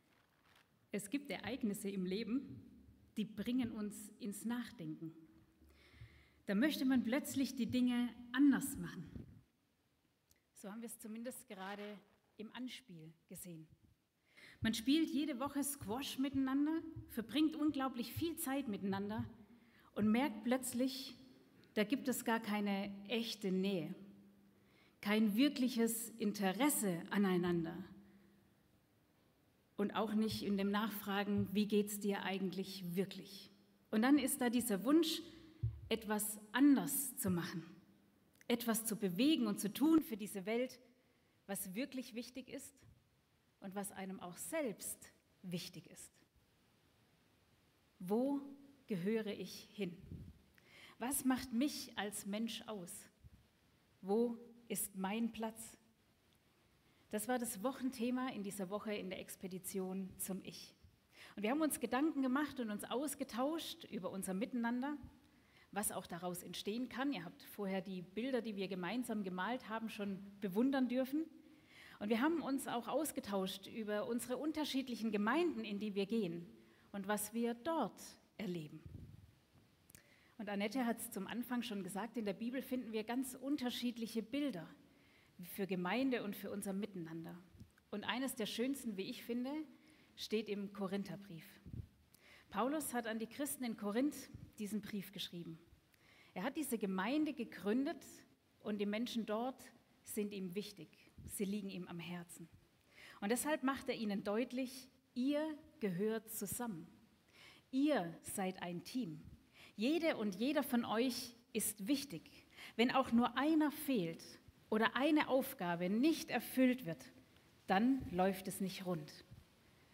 Gottesdienst vom 10.03.2024